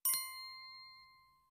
end_game.wav